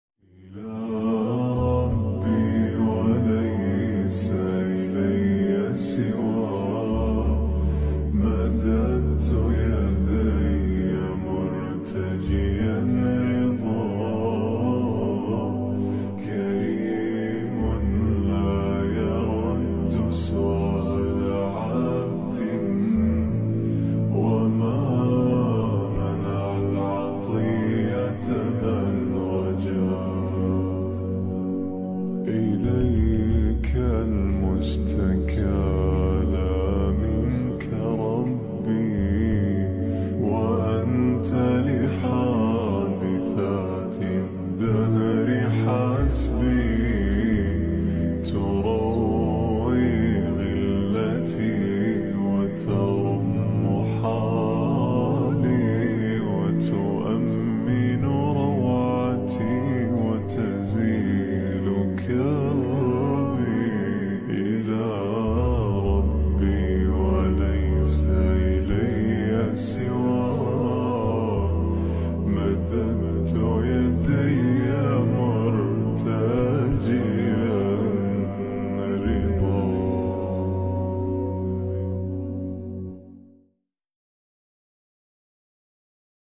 BEAUTIFUL NASHEED🤍😊 USE HEADPHONE FOR